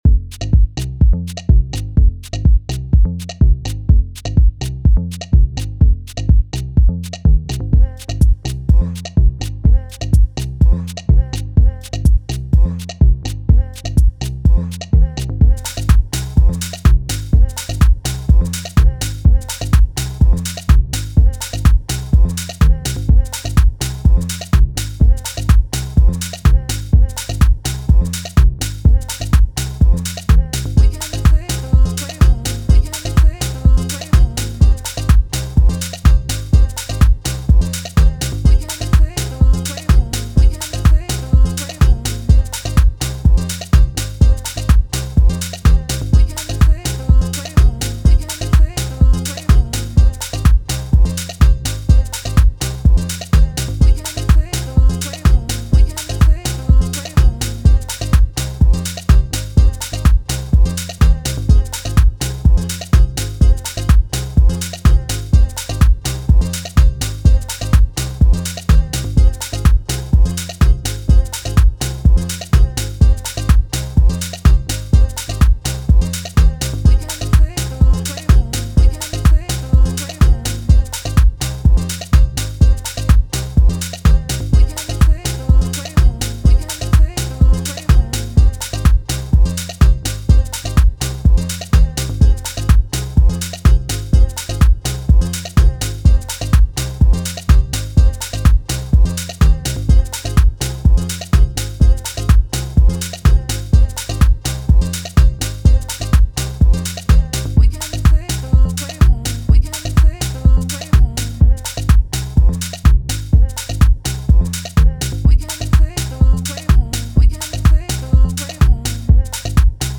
House, Dance
F Minor